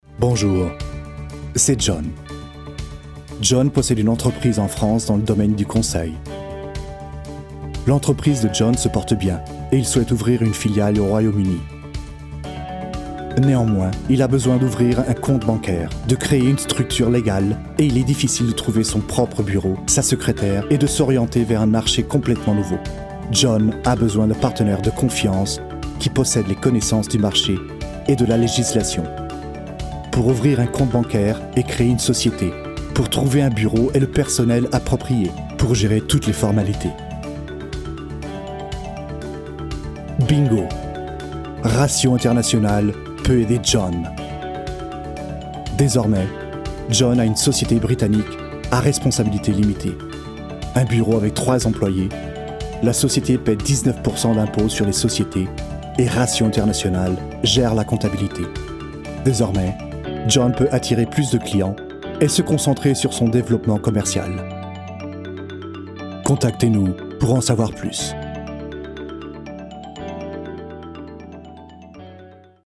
外籍法语男4-白板动画-网络金融